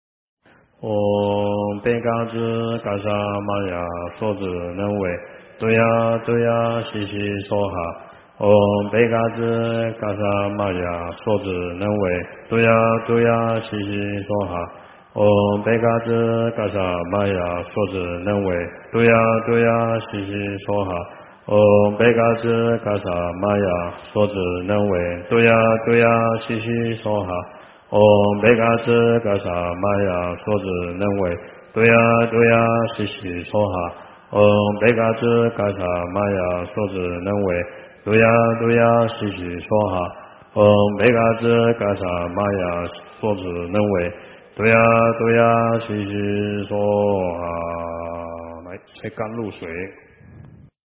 08治疗胃肠病咒 诵经 08治疗胃肠病咒--海涛法师 点我： 标签: 佛音 诵经 佛教音乐 返回列表 上一篇： 古筝佛赞 忆儿时 下一篇： 阿弥陀佛大乐心咒 相关文章 药师灌顶真言--黄慧音 药师灌顶真言--黄慧音...